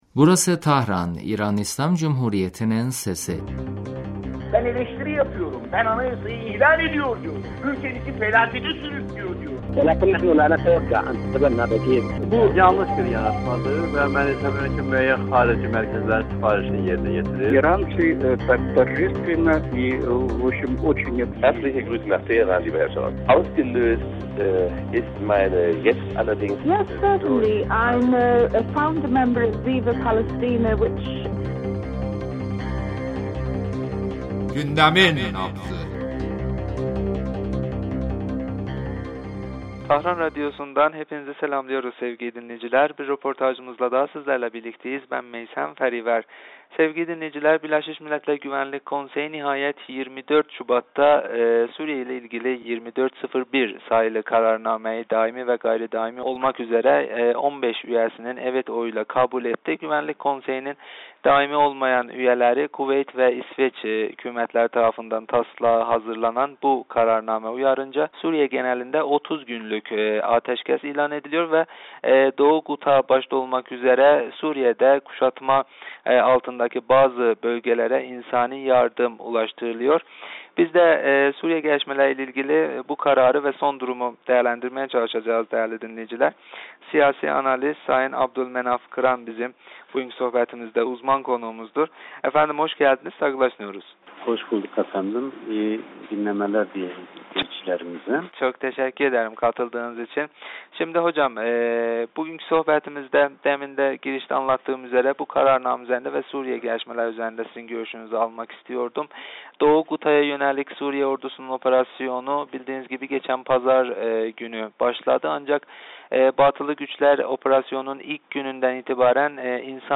ile yaptığımız telefon görüşmesinde Suriye gelişmeleri çerçevesinde BMGK'nin 30 günlük ateşkes kararı üzerinde konuştuk.